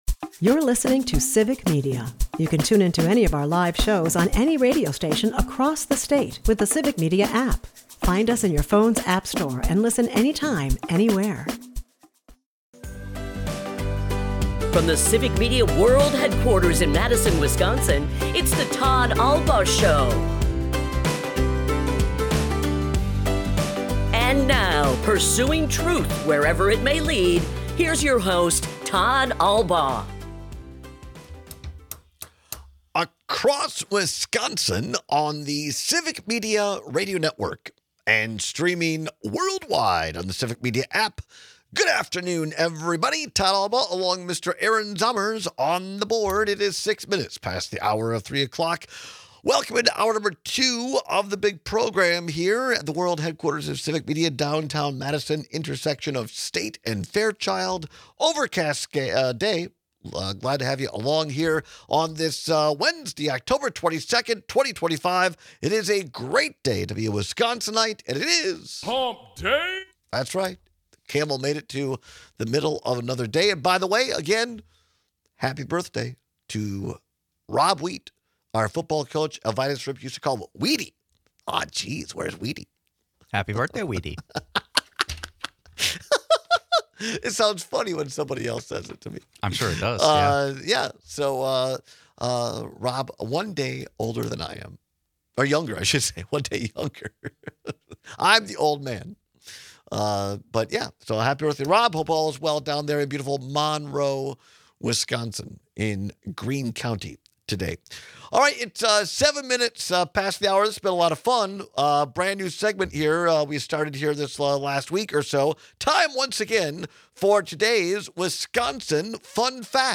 Also, why do we only shower married couples with gifts? We take your calls and texts.&nbsp